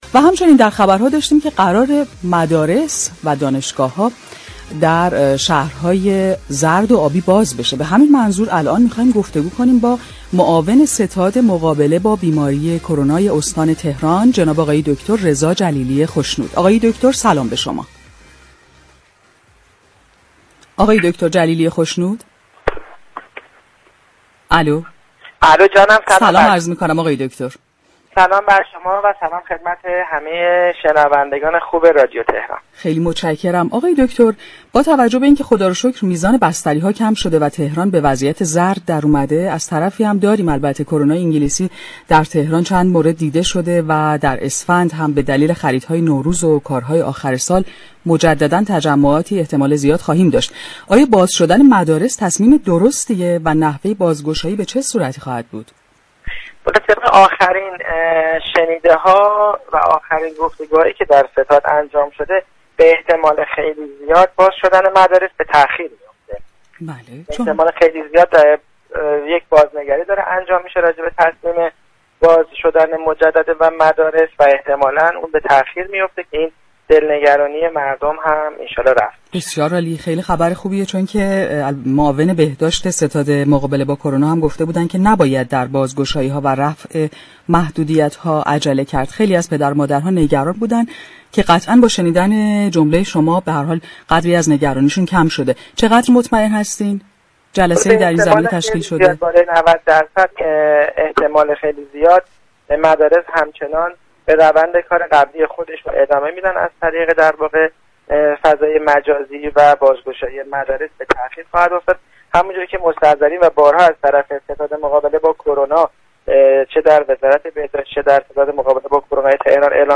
رضا جلیلی خشنود،معاون ستاد مقابله با كرونای استان تهران در گفت‌وگو با برنامه «تهران ما سلامت» رادیو تهران